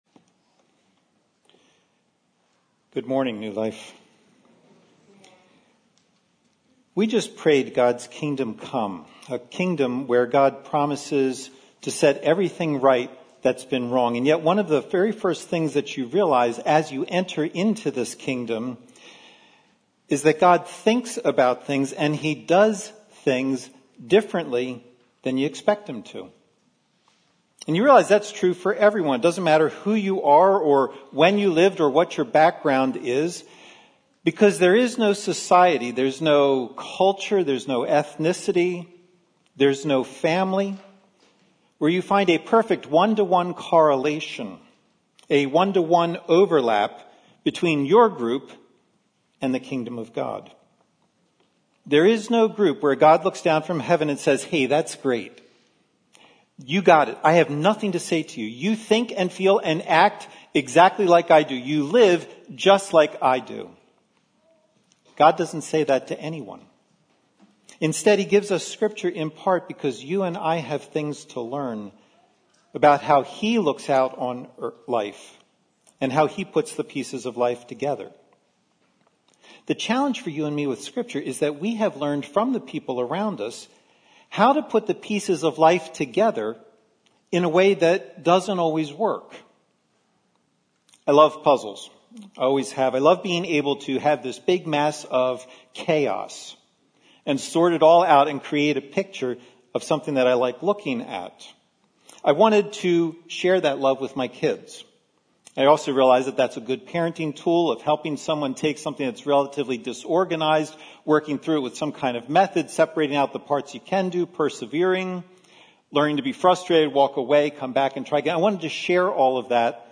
Sermons - New Life Glenside